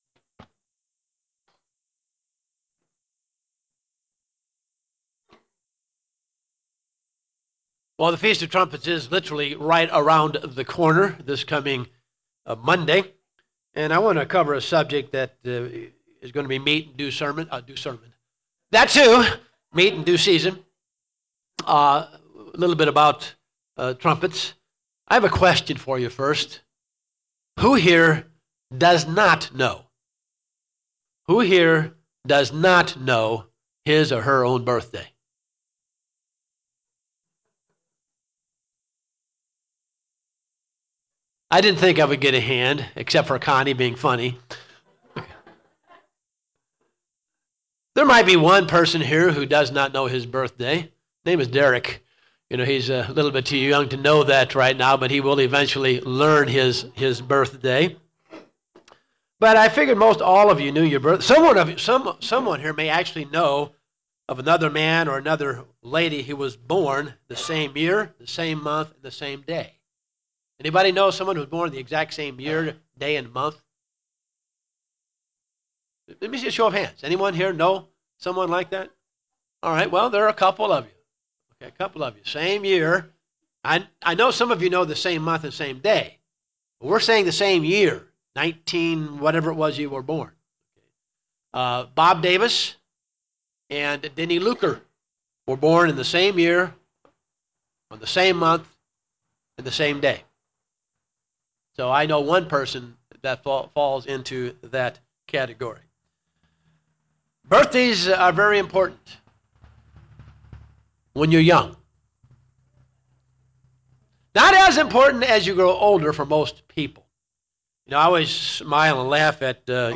Given in Cincinnati North, OH
UCG Sermon Studying the bible?